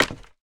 1.21.4 / assets / minecraft / sounds / step / scaffold4.ogg
scaffold4.ogg